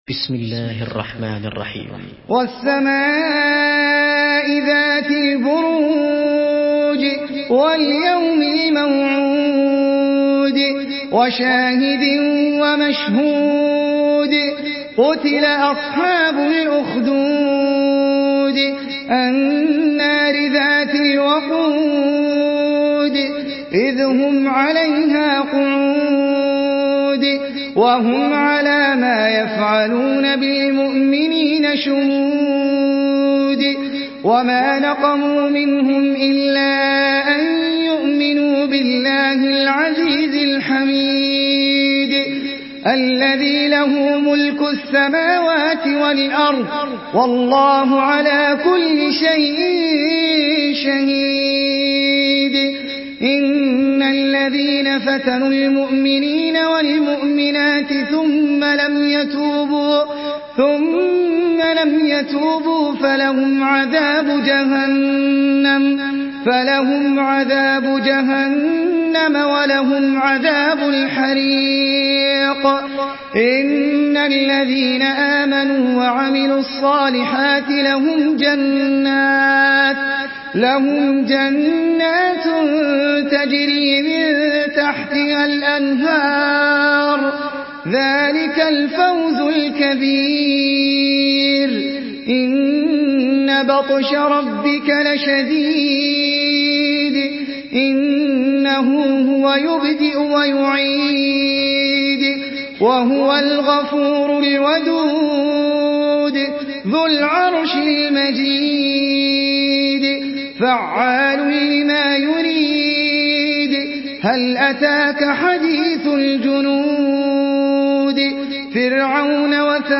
Surah Büruc MP3 in the Voice of Ahmed Al Ajmi in Hafs Narration
Murattal Hafs An Asim